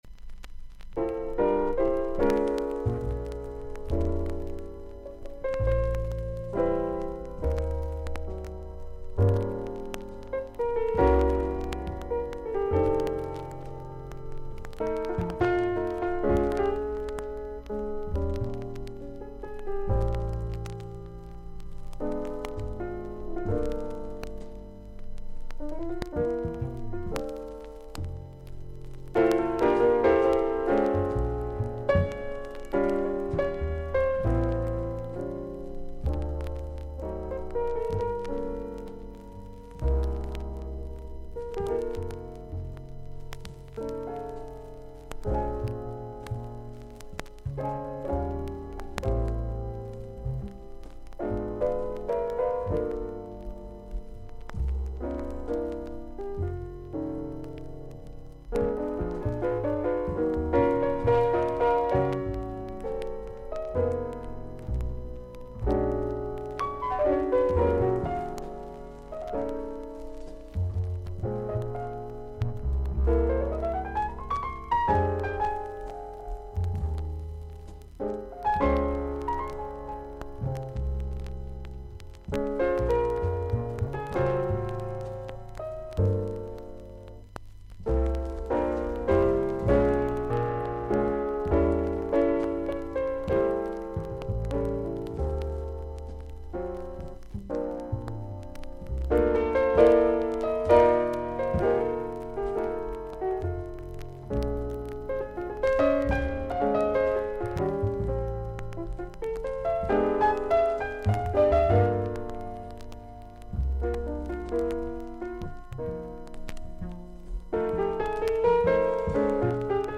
少々軽いノイズあり。
全体的にサーフィス・ノイズあり。音はクリアです。
ジャズ・ピアニスト。
リラックスした雰囲気でスタンダード・ナンバーを演奏しています。